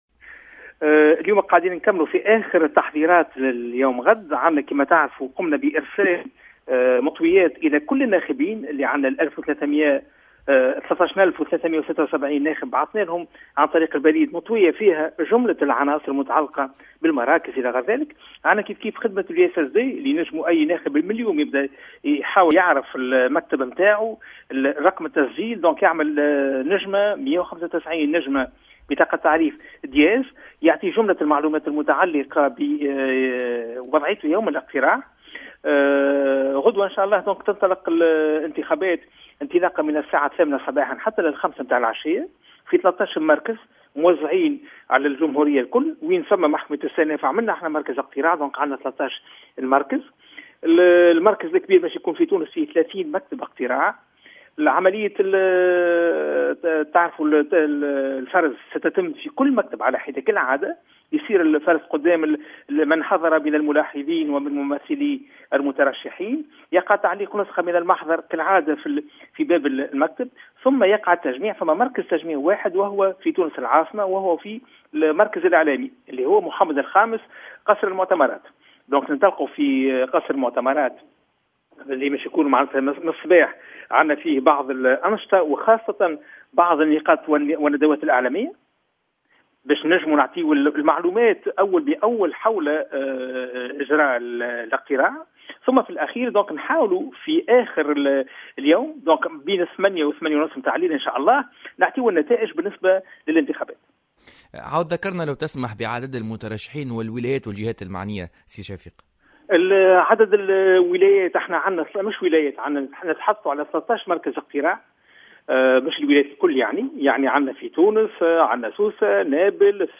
وأفاد رئيس هيئة الانتخابات، شفيق صرصار في حوار مع الجوهرة أف أم، أن 13.376 ناخبا سيتوجهون، بين الساعة الثامنة صباحا والخامسة مساء، إلى 106 مكتب اقتراع موجودة بـ 13 مركز اقتراع